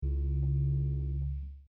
guitar hit 3 1 sec. mono 19k
guitarhit3.mp3